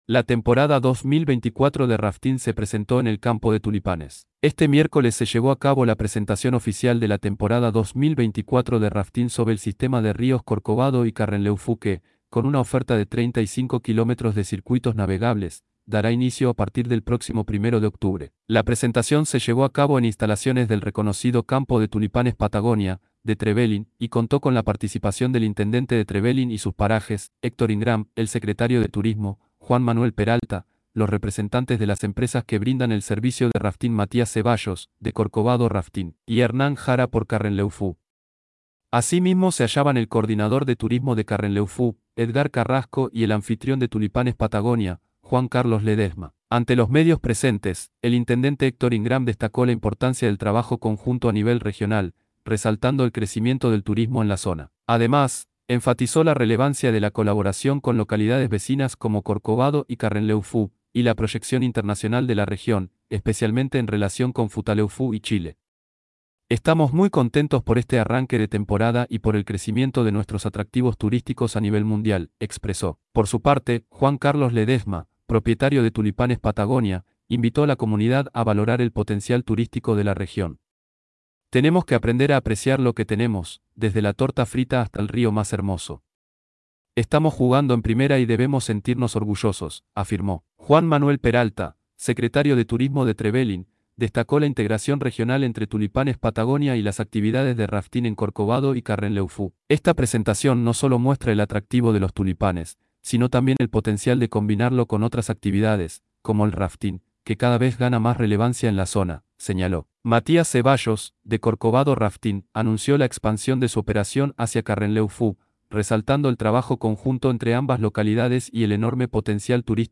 LA TEMPORADA 2024 DE RAFTING SE PRESENTÓ EN EL CAMPO DE TULIPANES